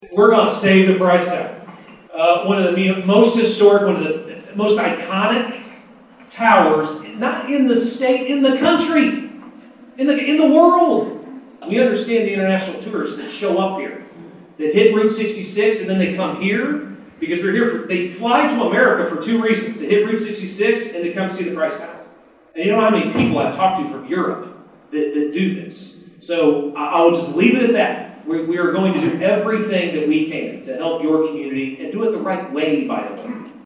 commitment for the troubled Frank Lloyd Wright icon with a resounding round of applause.
Matt Pinnell on Price Tower 10-15.mp3